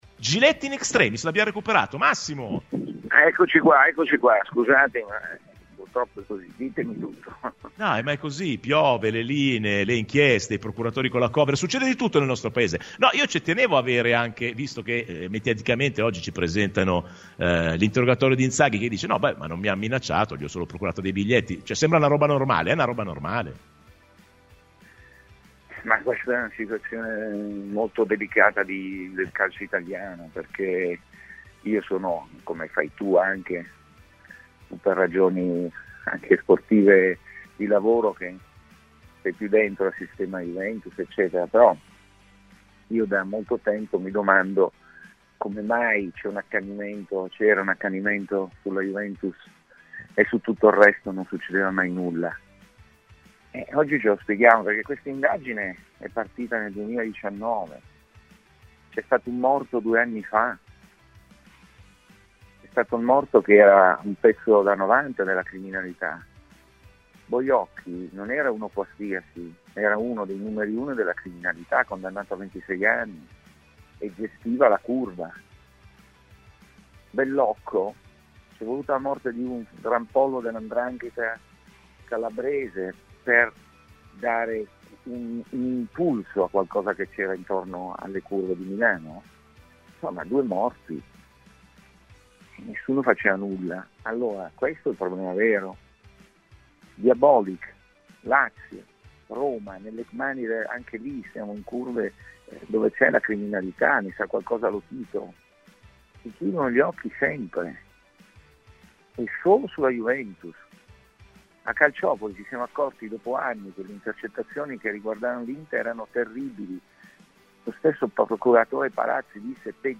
"La Juve non ha capito che ci vuole un uomo a Roma, nei palazzi della politica", ha spiegato tra le tante cose Massimo Giletti questa mattina ospite su Rbn di Rassegna Stramba.